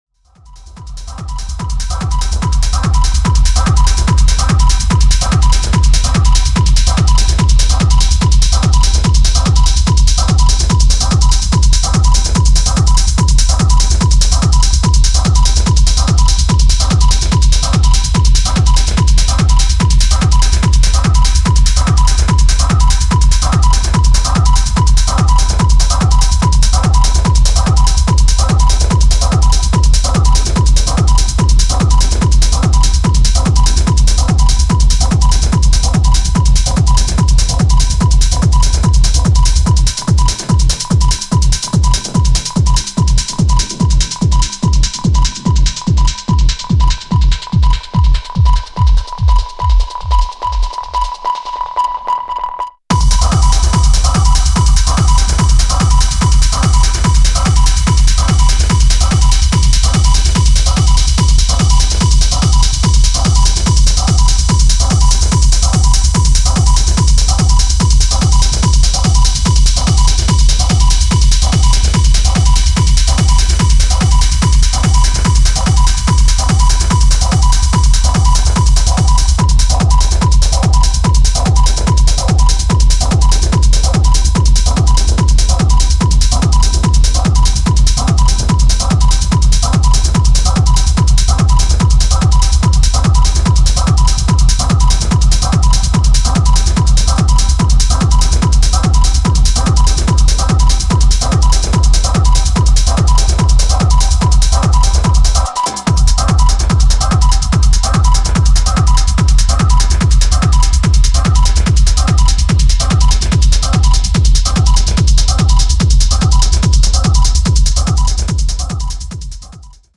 true raw and intense techno sound